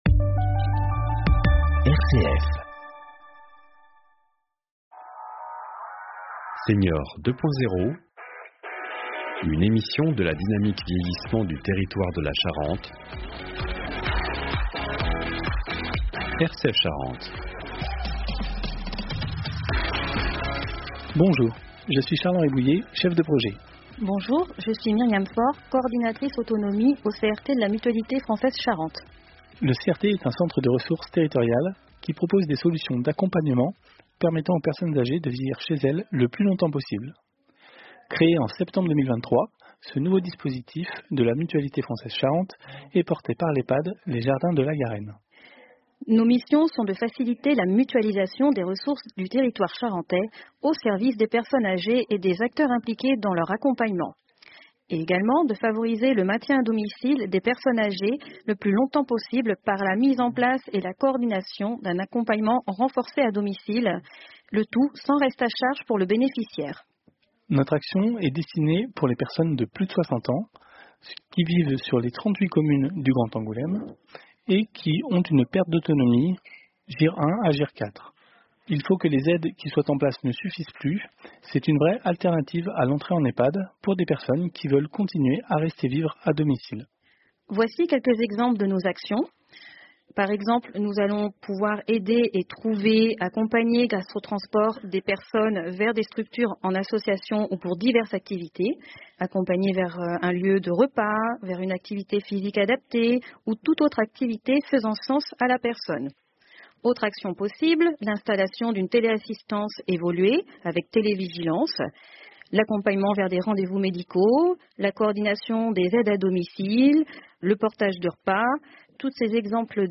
des témoignages d'usagers ou de proches